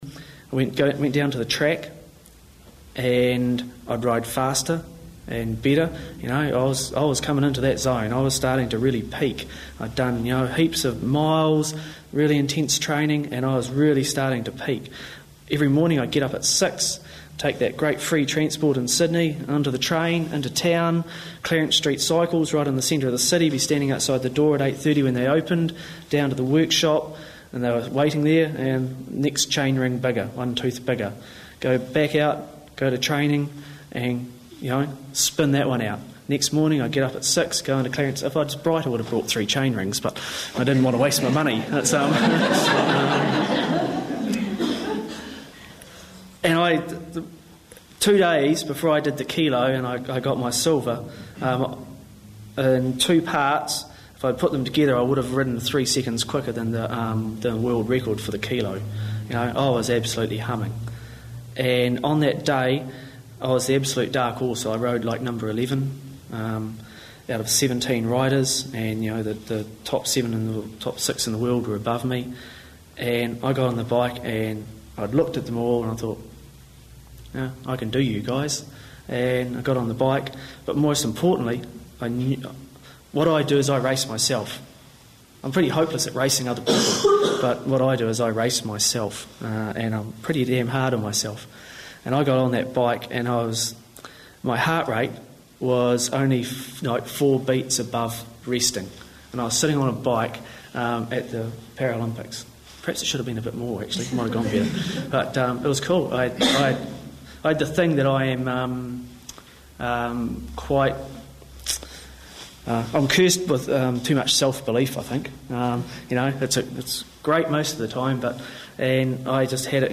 These sound tracks were produced from videos made at each of the Peak Performance Seminars.
Video and audio quality is not good for all events due to local venue conditions.